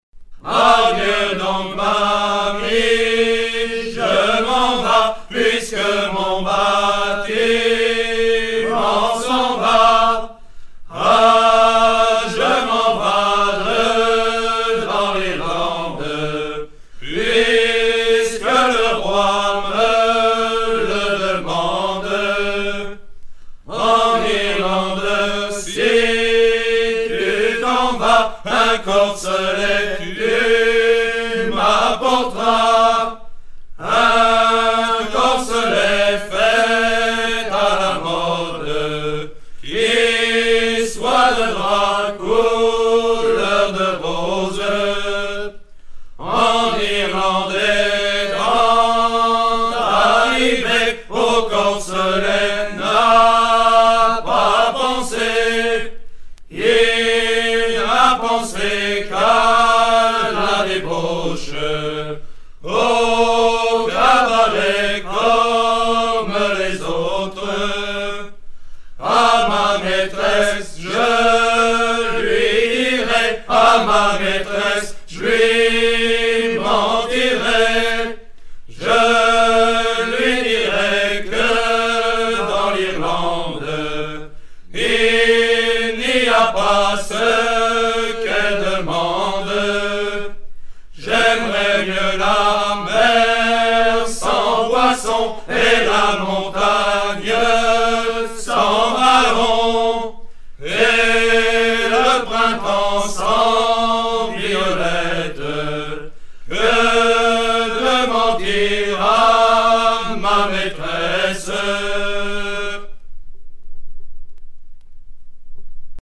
Version recueillie en 1960 auprès d'un chanteur anonyme d'Ocqueville
Genre strophique